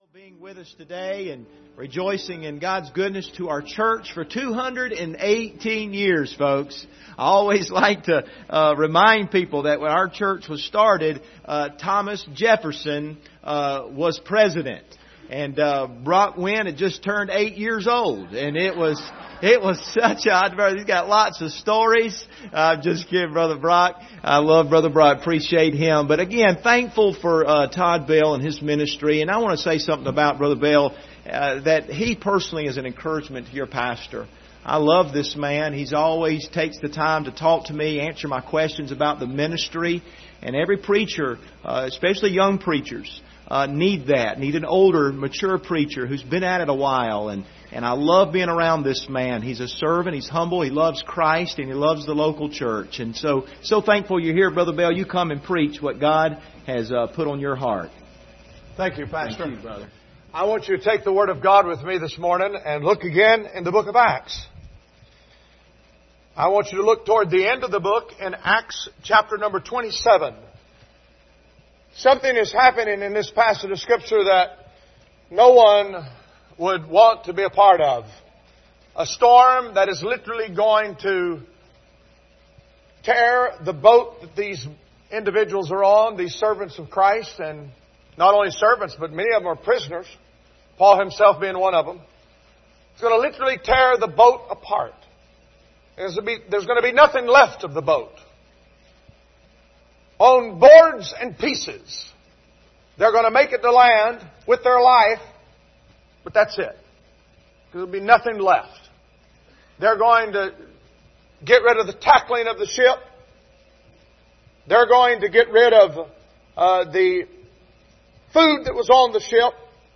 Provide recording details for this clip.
Passage: Acts 27 Service Type: Sunday Morning Download Files Bulletin « These That Have Turned the World Upside Down Jehovah Tsidkenu